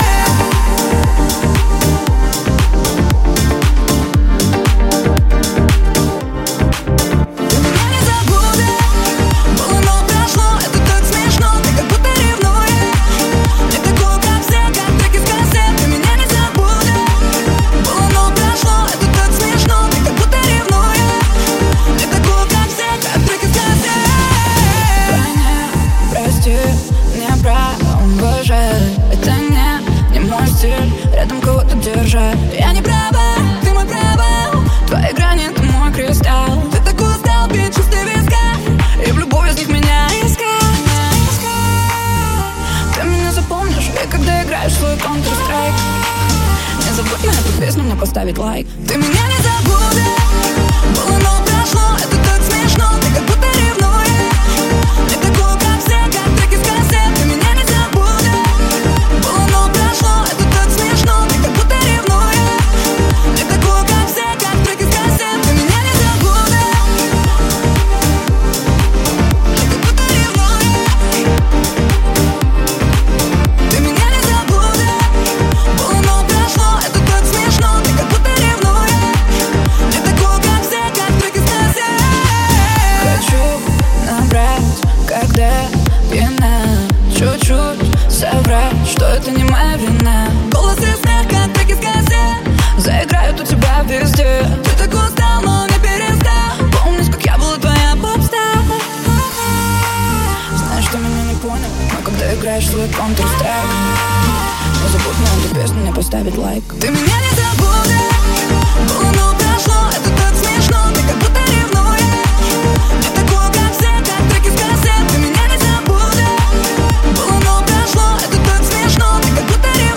Жанр: Жанры / Электроника